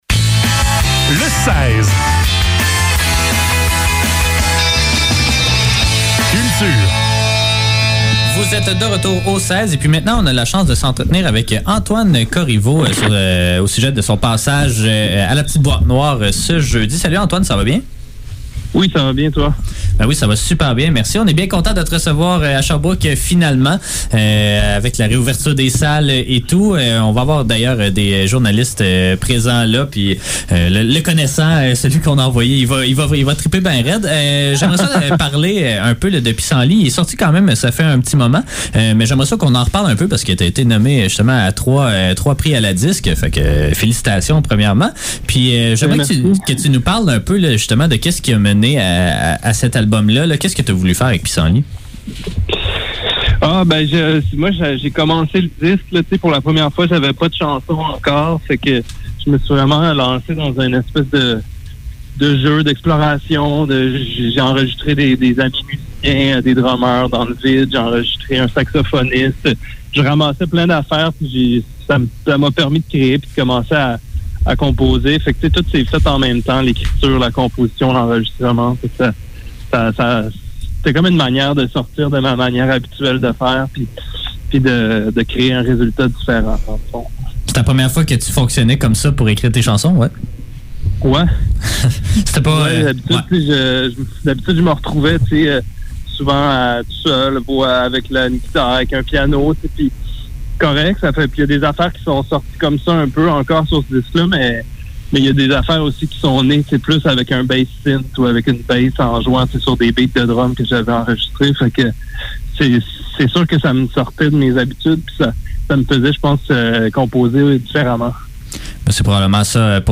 Les entrevues de CFAK